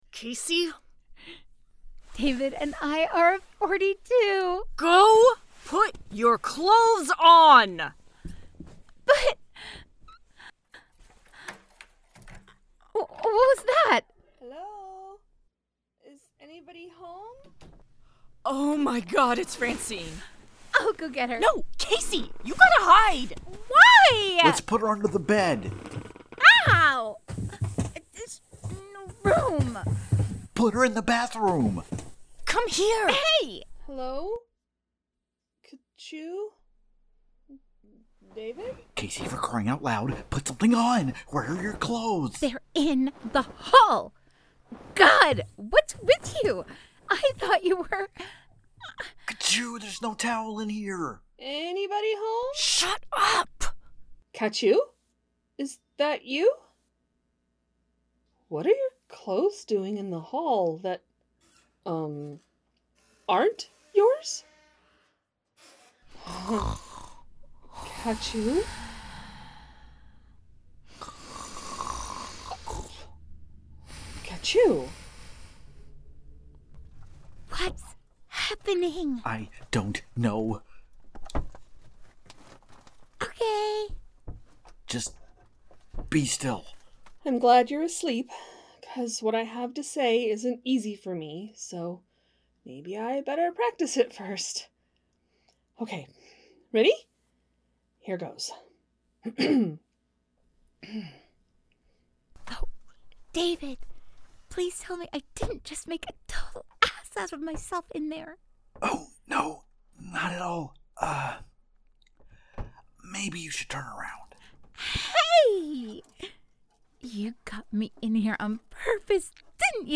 strangers-in-paradise-the-audio-drama-book-7-episode-15